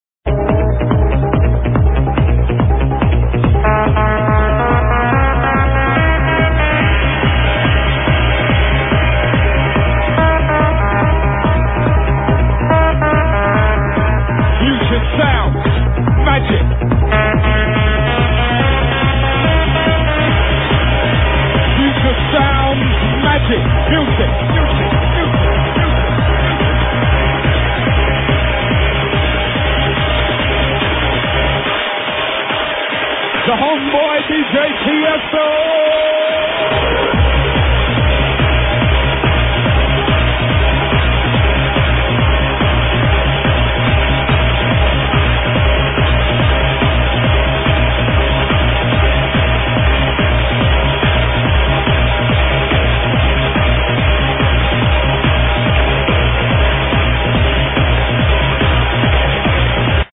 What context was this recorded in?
live at breda